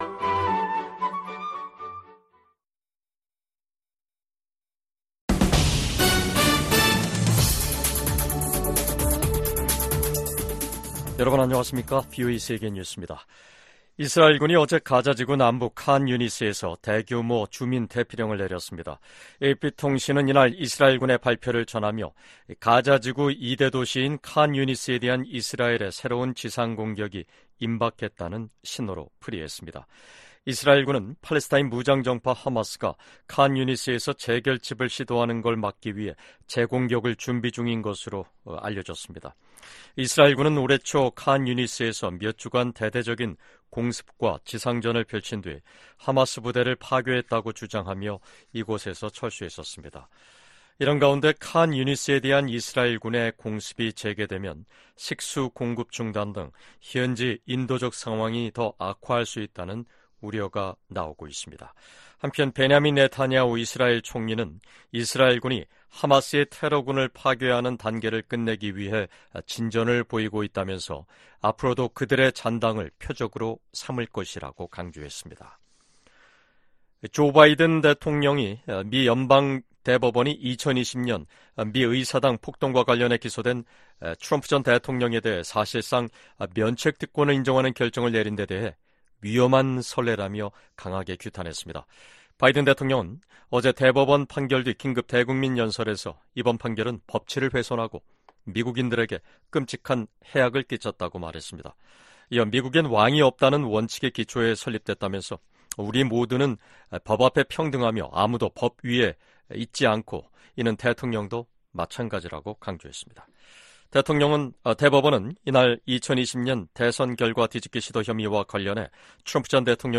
VOA 한국어 간판 뉴스 프로그램 '뉴스 투데이', 2024년 7월 2일 2부 방송입니다. 지난해 10월 7일 하마스의 이스라엘 공격 당시 피해를 입은 미국인들이 북한 등을 상대로 최소 40억 달러에 달하는 손해배상 소송을 제기했습니다. 북한은 어제(1일) 초대형 탄두를 장착하는 신형 전술탄도미사일 시험발사에 성공했다고 밝혔습니다. 한국 군 당국은 미사일이 평양 인근에 떨어진 점 등을 들어 북한의 발표가 기만일 가능성에 무게를 두고 있습니다.